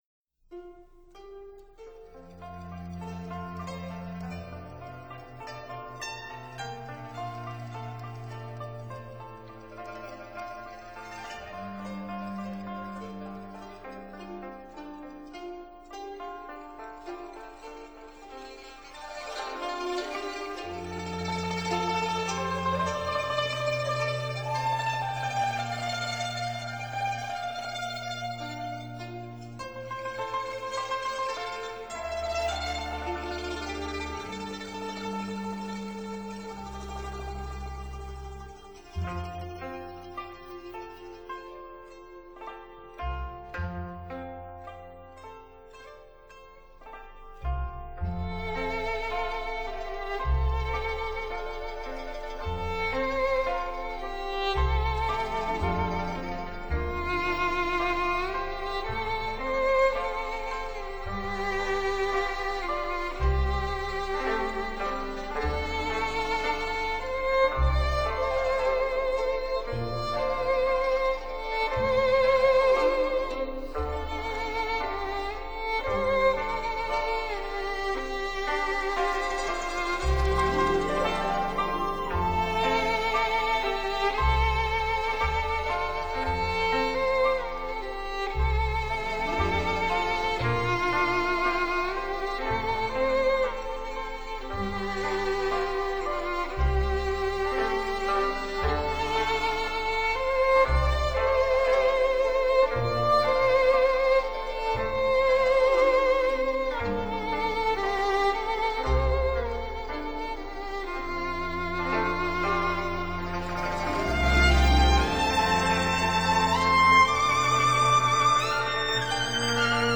发烧录音专辑
小提琴演奏
弦声凄美动人！